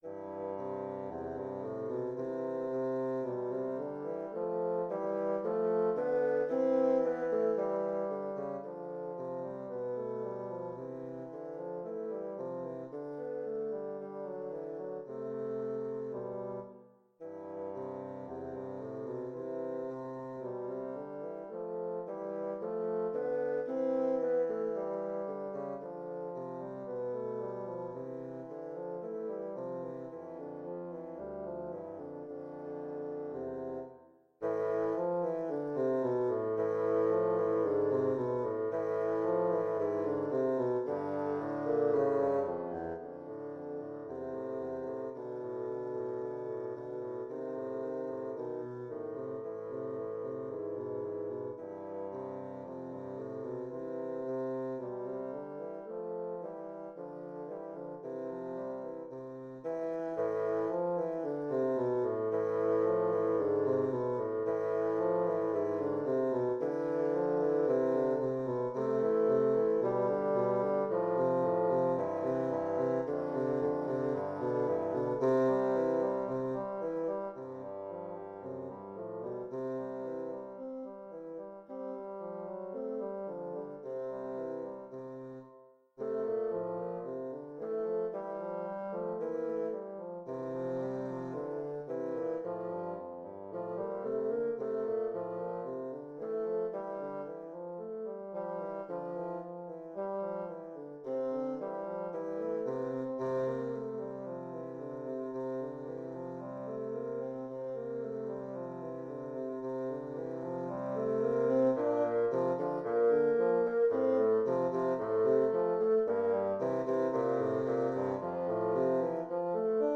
Intermediate bassoon duet
Instrumentation: bassoon duet